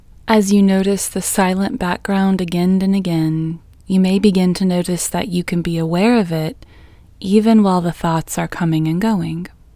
QUIETNESS Female English 11
Quietness-Female-11-1.mp3